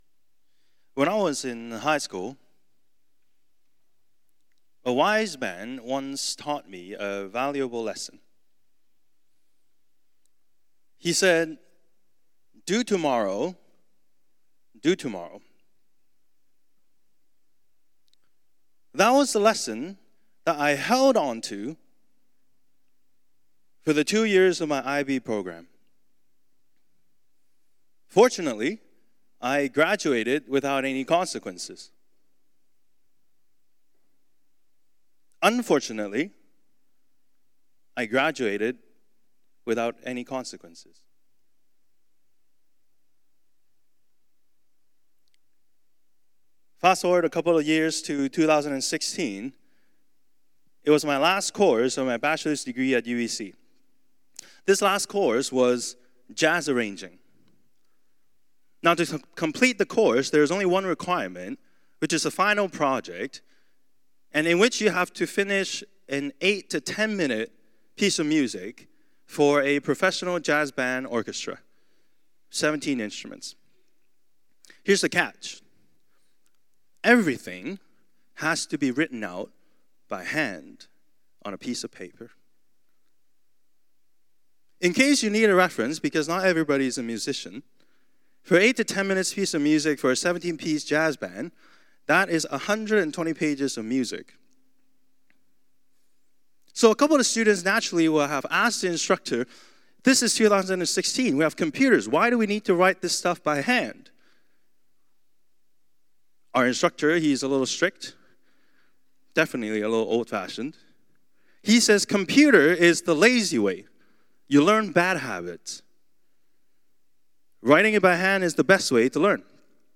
Download Download Foolproof Current Sermon Due Tomorrow, Do Tomorrow.